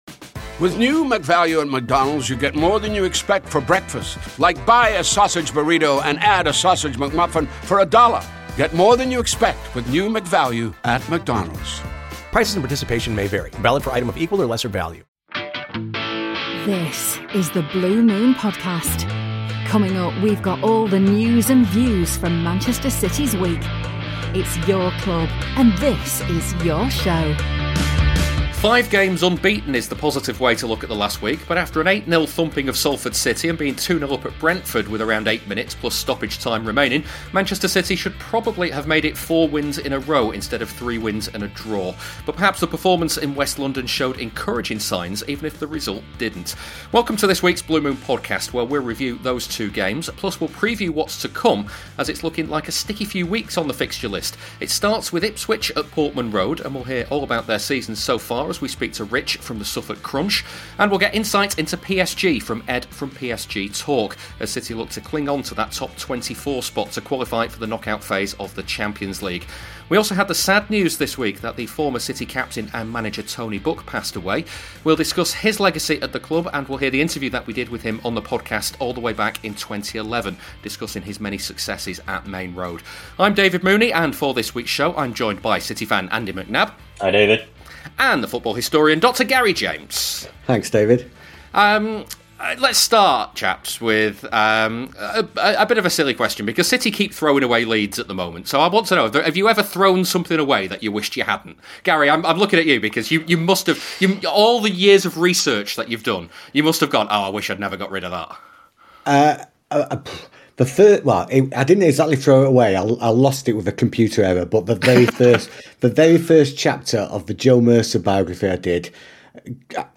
After the sad news of the death of City legend Tony Book, we replay the interview we did with him from May 2011 and we discuss his legacy at the club.